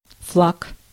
Ääntäminen
US : IPA : [flæɡ]